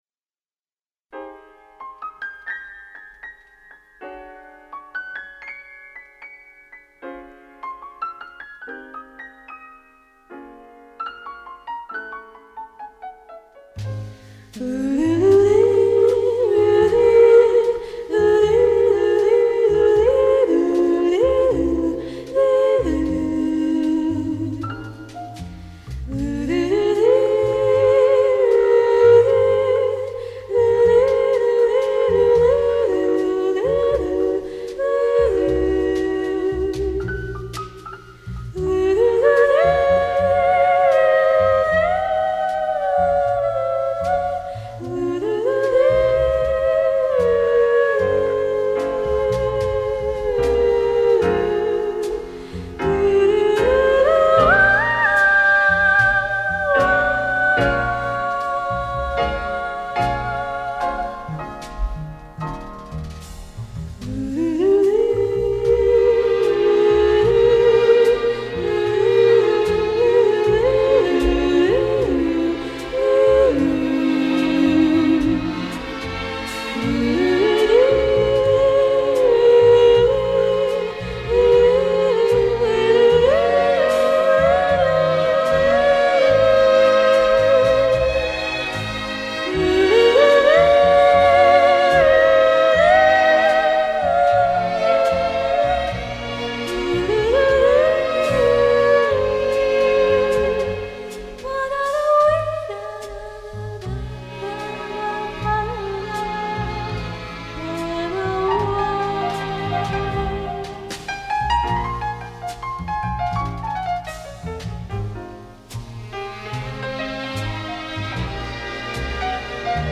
Лирический вокализ - шикарно!